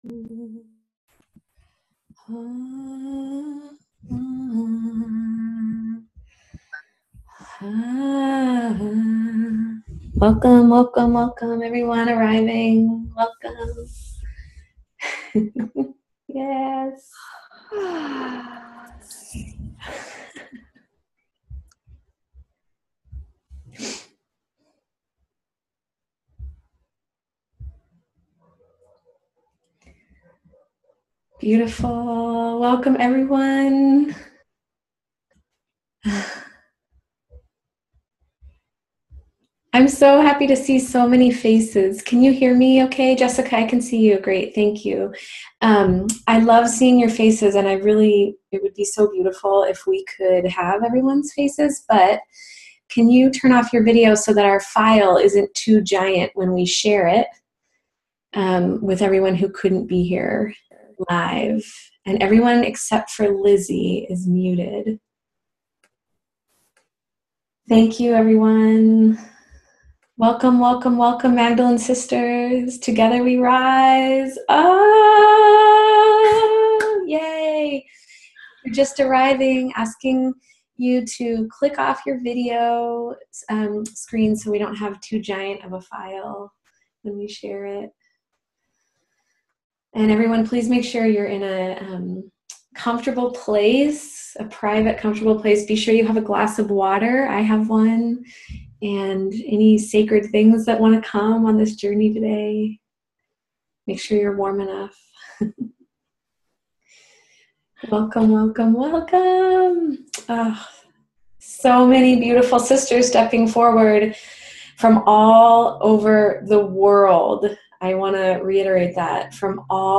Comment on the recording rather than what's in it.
Listen to our ceremony here